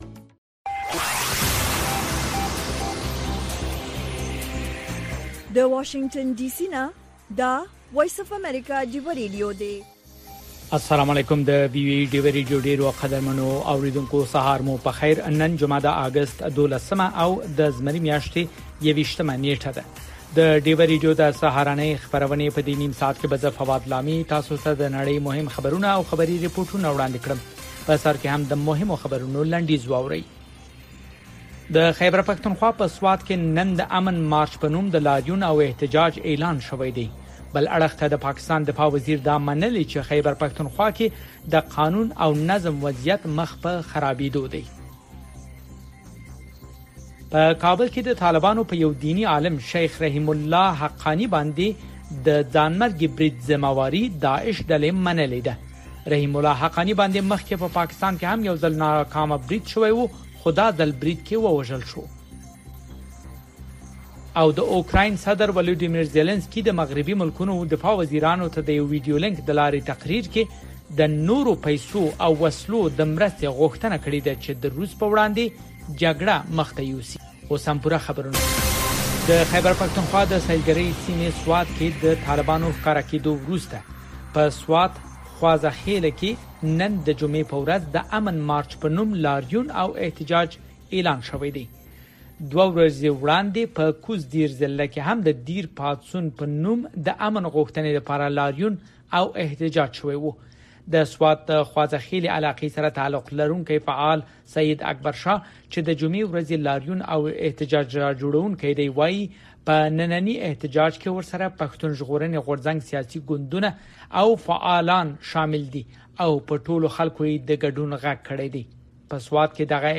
د وی او اې ډيوه راډيو سهرنې خبرونه چالان کړئ اؤ د ورځې دمهمو تازه خبرونو سرليکونه واورئ. په دغه خبرونو کې د نړيوالو، سيمه ايزو اؤمقامى خبرونو هغه مهم اړخونه چې سيمې اؤ پښتنې ټولنې پورې اړه لري شامل دي.